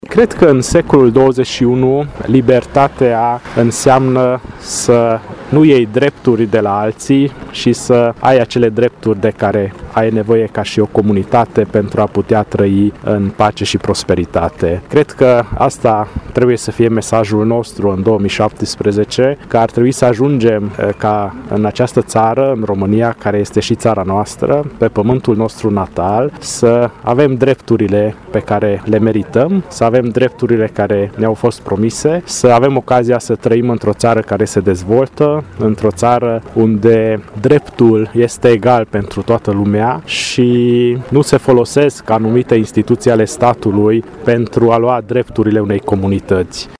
Manifestarea a avut loc la statuia păsării Turul şi a cuprins o evocare istorică, un moment artistic şi o slujbă religioasă.
Printre participanţi s-a numărat şi primarul municipiului Sfântu Gheorghe, Antal Arpad, care a declarat că mesajul acestei zile este că membrii comunităţii maghiare din România cer să li se acorde şi să li se respecte drepturile ce li se cuvin: